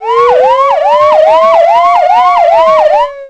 emergency.wav